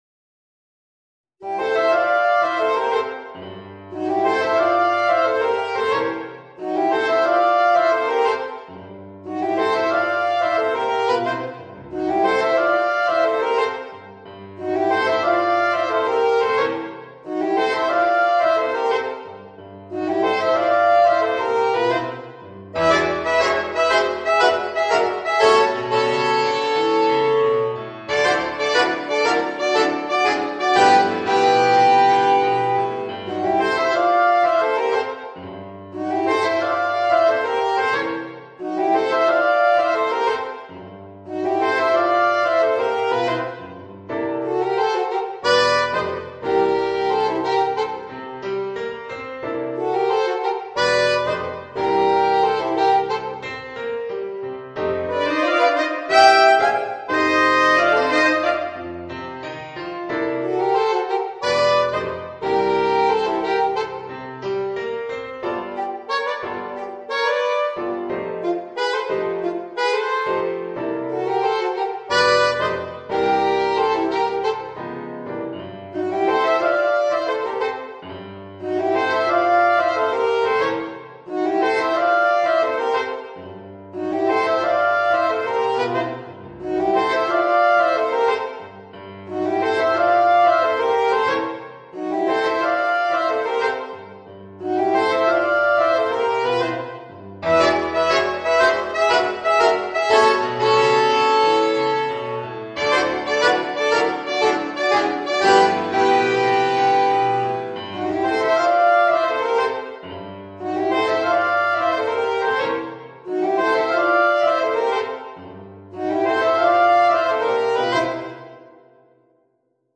Voicing: 2 Alto Saxophones w/ Audio